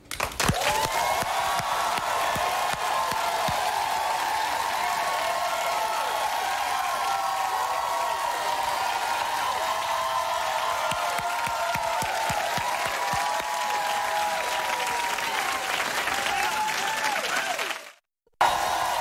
Interviews Wall Street Journal Café August 1, 2012 Mail Mail Description: Regina Spektor discusses her emotional return to Russia, reflecting on how it triggered memories and strengthened her connection to her childhood.